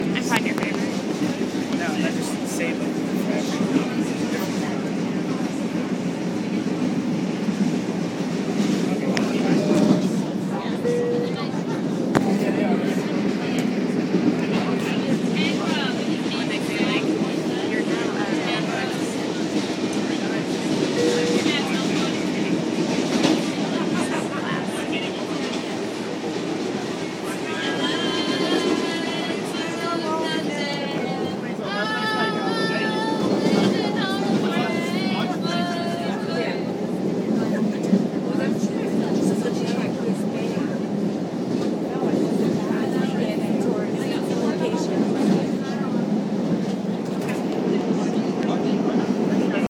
Field Recording
Train on tracks, ventilation system, ding meaning we are reaching a station, talking, old lady singing Bon Jovi